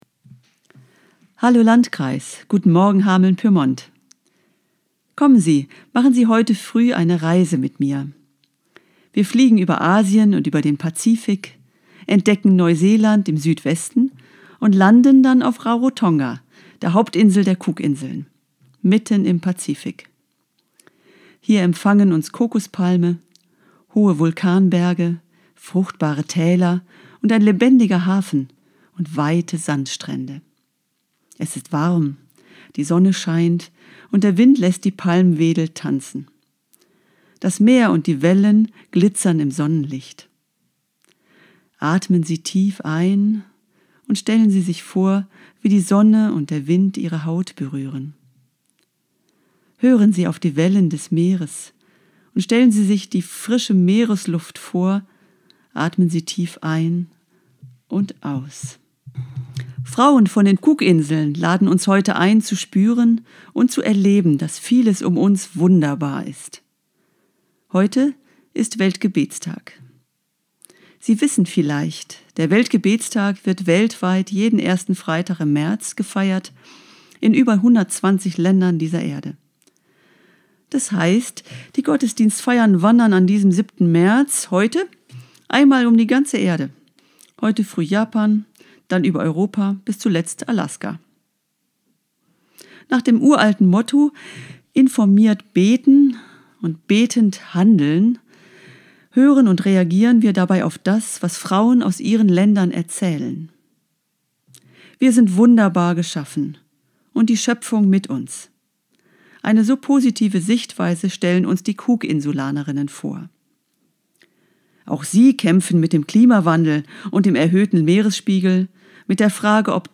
Radioandacht vom 7. März